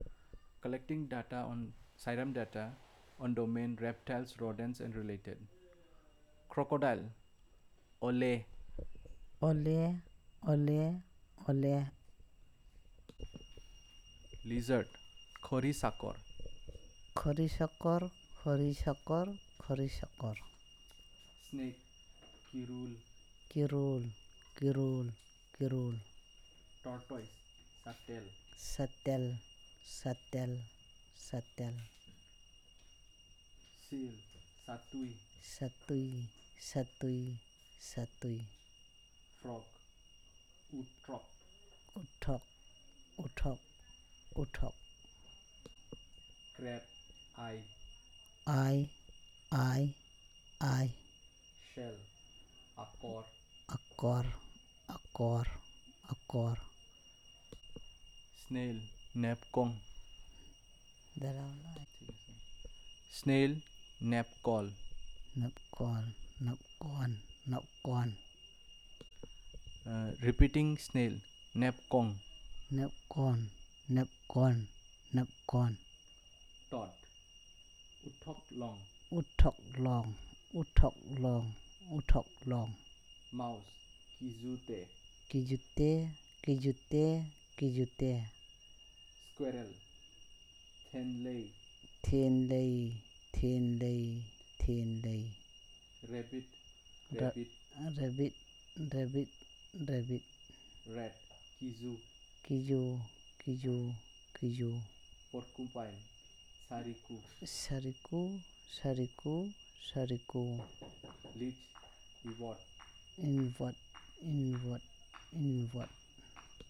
Elicitation of words about reptiles, rodents and related
NotesThis is an elicitation of words about reptiles, rodents and related using the SPPEL Language Documentation Handbook.